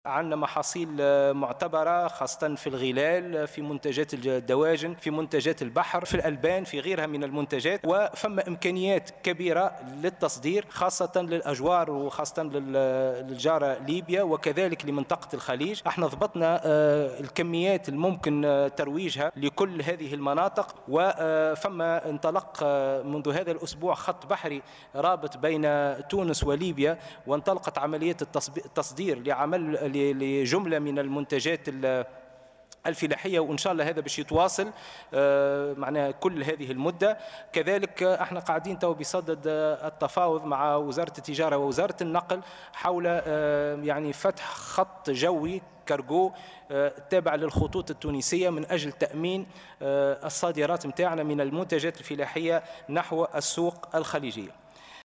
وكشف الخريجي خلال جلسة عامة لمجلس نواب الشعب، اليوم الثلاثاء 14 أفريل 2020 عن وجود مفاوضات قائمة بين وزارة الفلاحة ووزارتي النقل والتجارة لفتح خط شحن جوي تابع للخطوط التونسية لتامين الصادرات التونسية نحو السوق الخليجية.